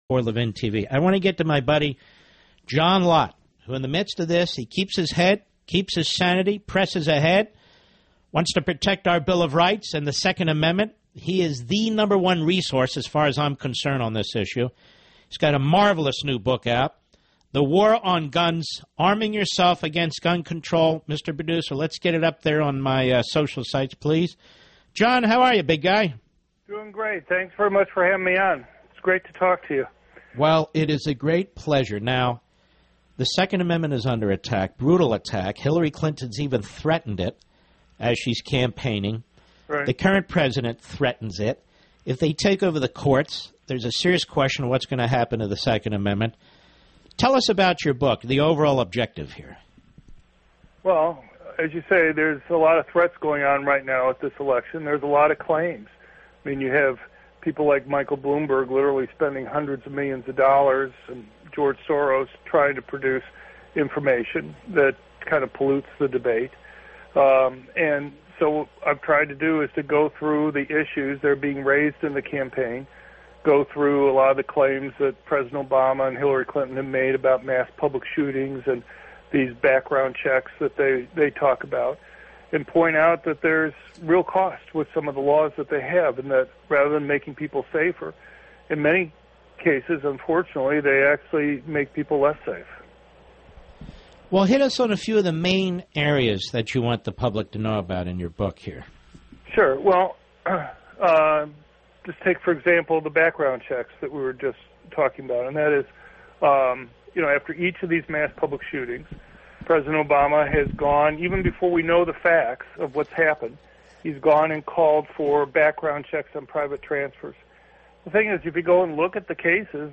media appearance
Dr. John Lott appeared on the Mark Levin show to discuss Lott’s new book, “The War on Guns.” (August 5th, 2016 8:38 to 8:46 PM)